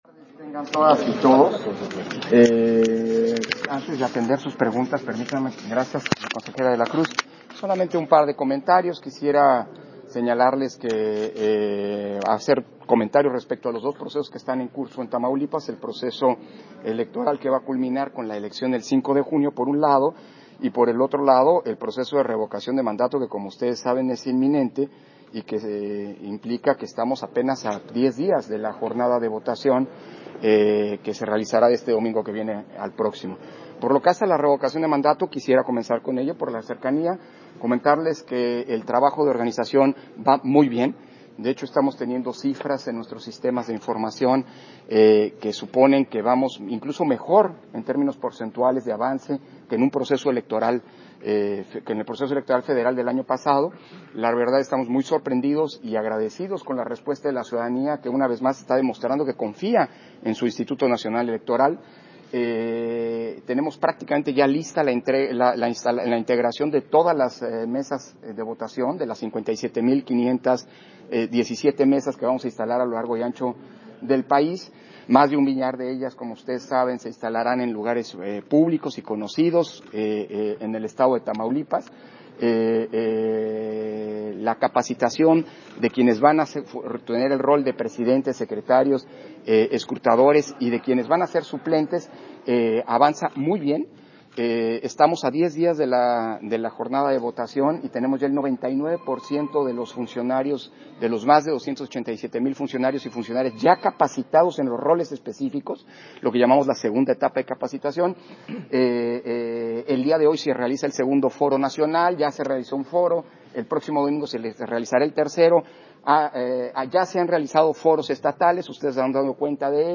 310322_AUDIO_ENTREVISTA-AL-CONSEJERO-PDTE.-CÓRDOVA-VISITA-DE-TRABAJO-TAMAULIPAS - Central Electoral